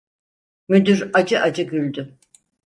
Pronúnciase como (IPA)
[ɑˈd̠̠͡ʒɯ]